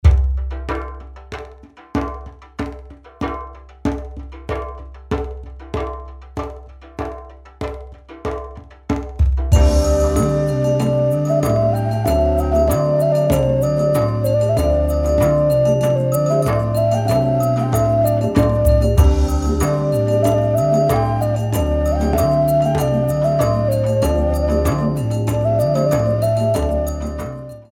fifteen beats